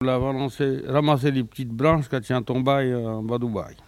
Patois - archives